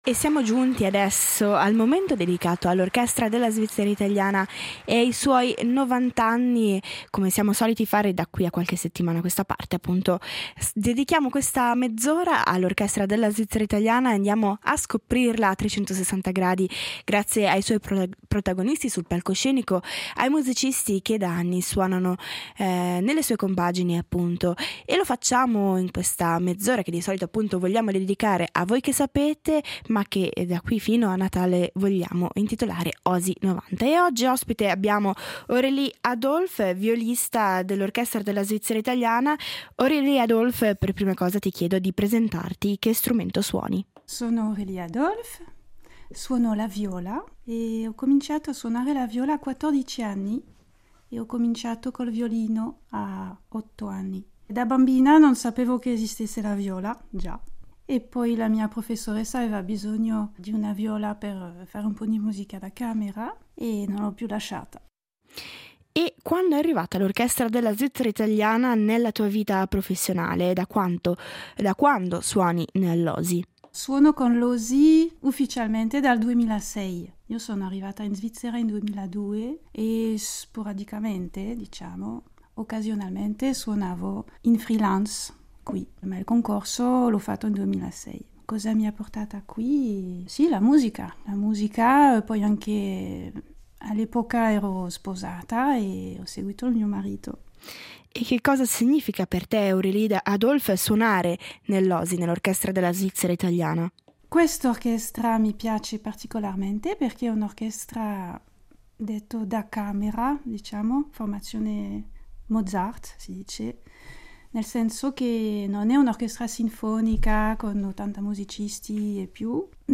Una serie di interviste per raccontare l’Orchestra della Svizzera italiana